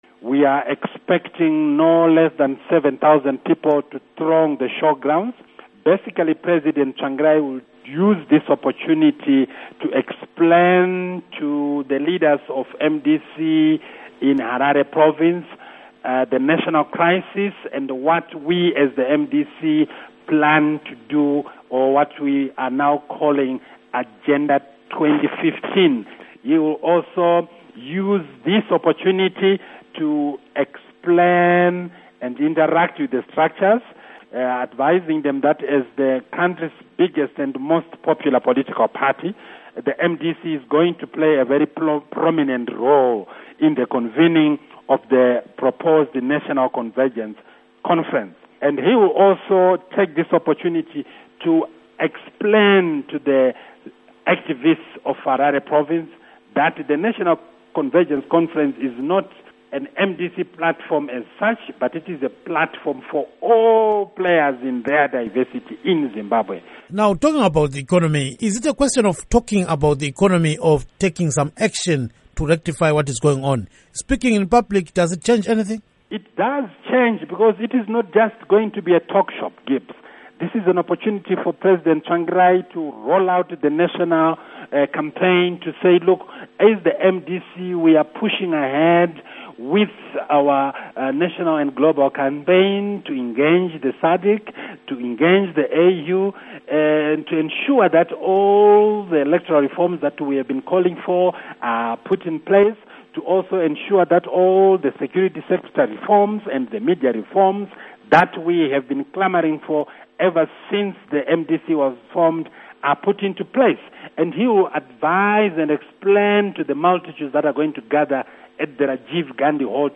Interview With MDC-T Spokesman Obert Gutu on Tsvangirai Meeting